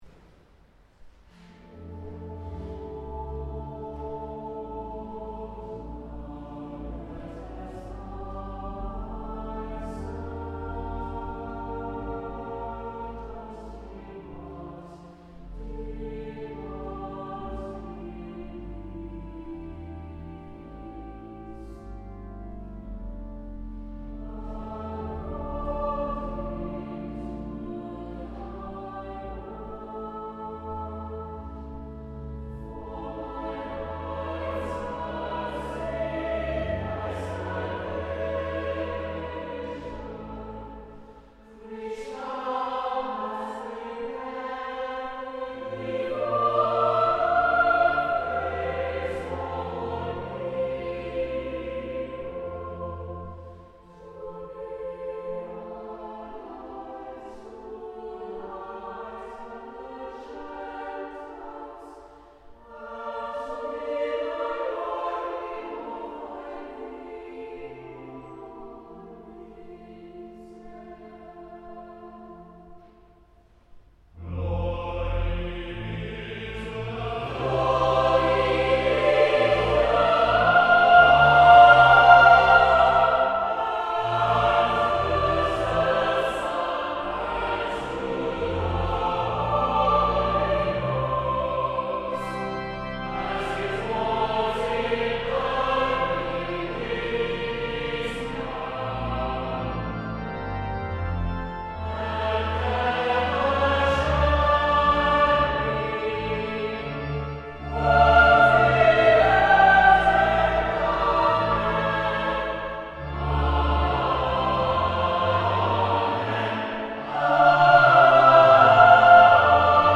Live Recordings
The recordings are not professional quality, and were taken with a single microphone during a live performance.